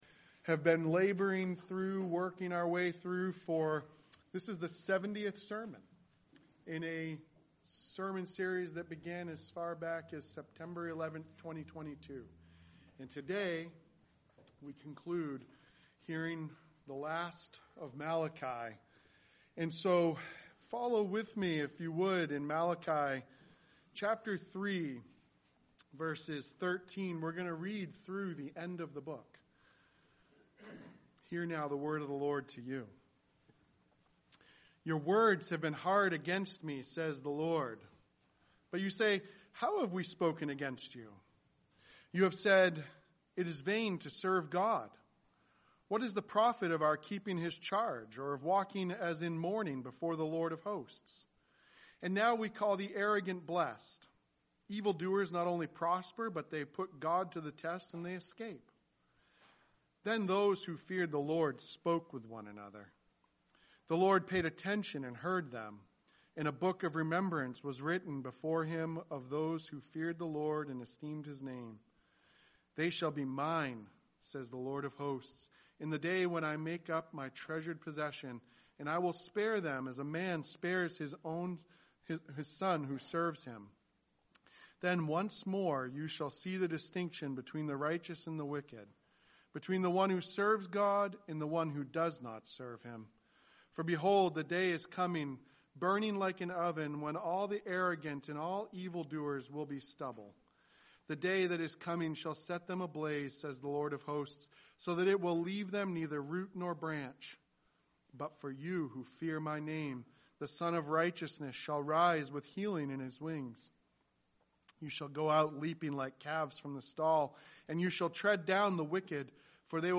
A Presbyterian Church (PCA) serving Lewiston and Auburn in Central Maine
sermon